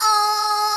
WailLoop.wav